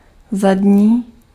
Ääntäminen
UK : IPA : /bæk/ US : IPA : /bæk/